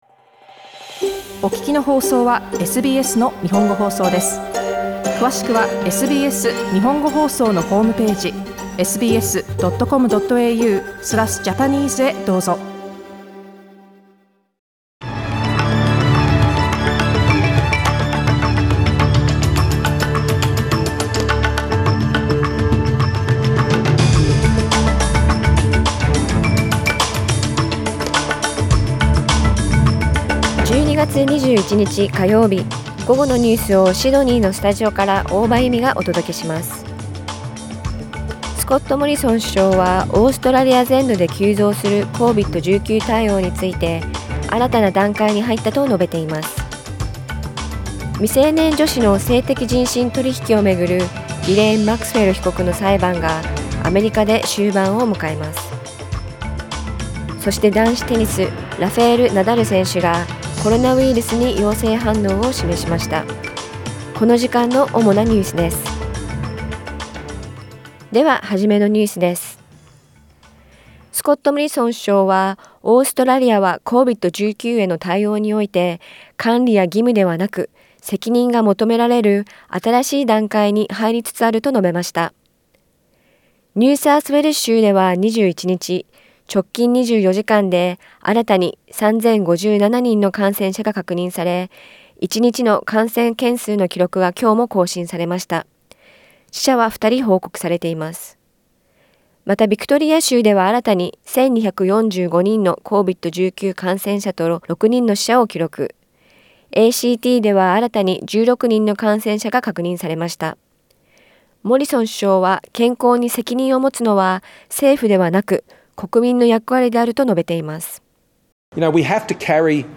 12月21日午後のニュース
Afternoon news in Japanese, 21 December 2021